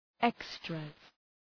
Shkrimi fonetik {‘ekstrəz}